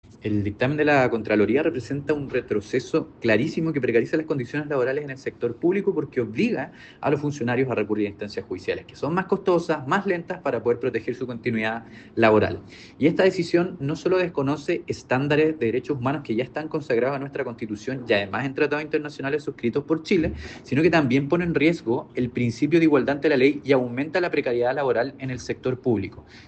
El diputado Giordano expresó que el reciente dictamen emitido por la contralora Dorothy Pérez “representa un retroceso que precariza las condiciones laborales en el sector público, al obligar a los funcionarios a recurrir a instancias judiciales más costosas y lentas para proteger su continuidad laboral”.